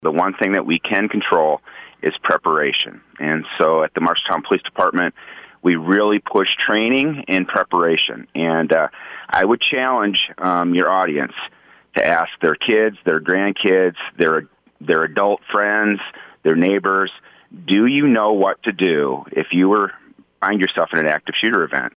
Marshalltown Police Chief Mike Tupper joined the KFJB line to talk about what we can do if it happens here.